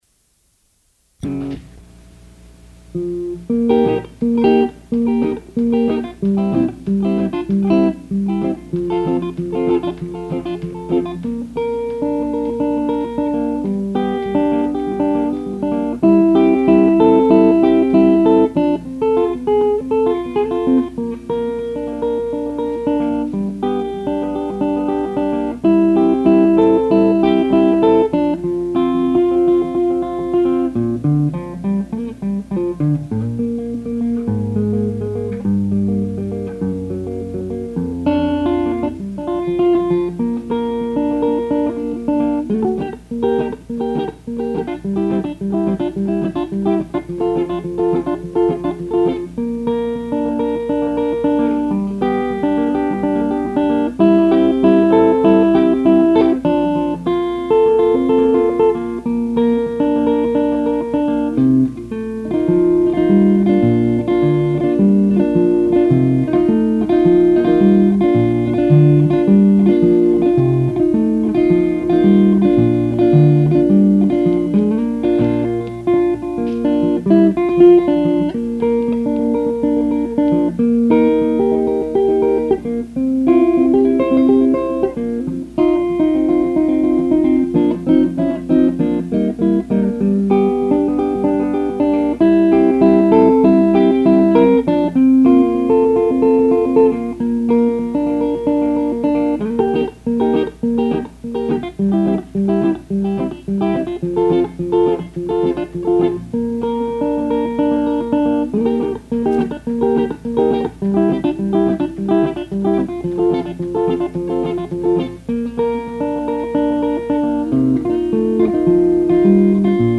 On November 6, 1969, 9:45pm-12mid, we broadcast “Grassroots” live Broadcast from the Kirkland Coffee House.
and  I kept a 1/4″ reel-to-reel magnetic tape from the program for 35 years.
guitar solo